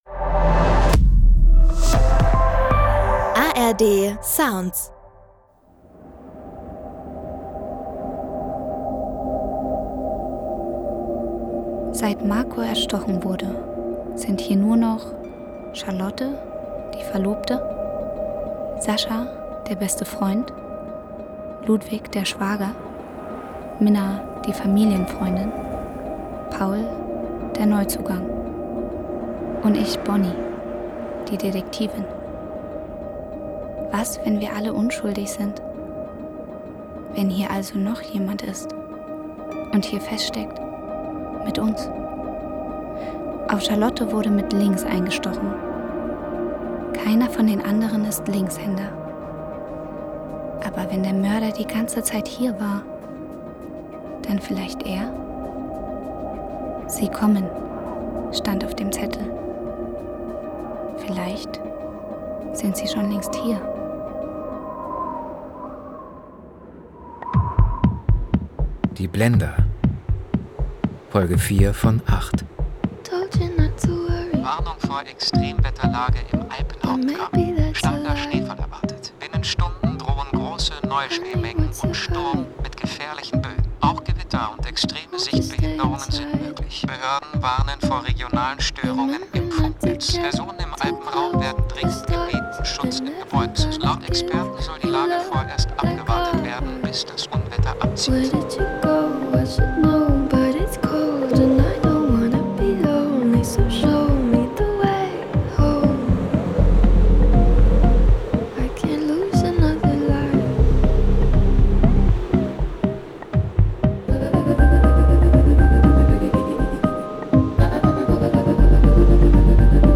Die Blender: Sascha (4/8) – Spur im Schnee ~ Die Blender – Crime-Hörspiel-Serie Podcast